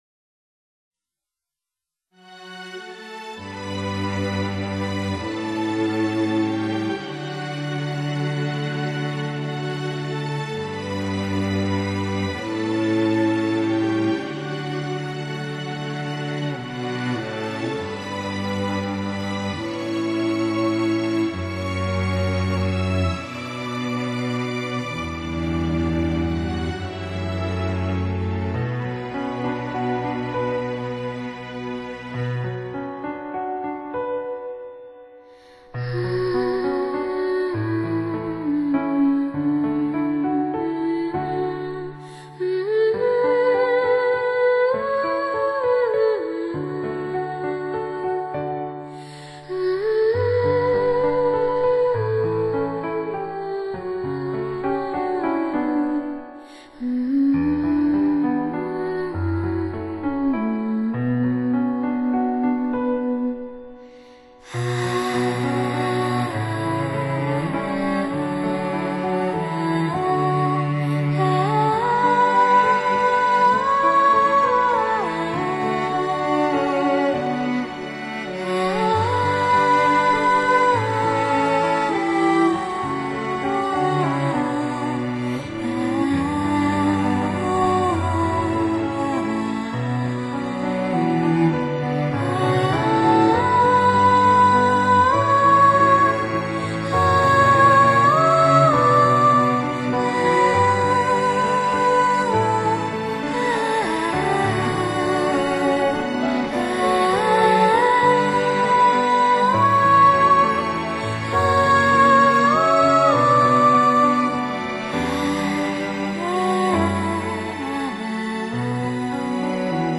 发烧人声
层次感明显加强，前后左右的空间范围也相应提高，
每种乐器的位置亦清晰的安放好，婀娜多姿的美态都活灵活现于阁下耳前，
音乐的感觉也十分煽情，好像要跟你倾谈一下心底夜话一般。